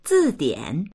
zìdiǎn 字典 3 字典、辞書
zi4dian3.mp3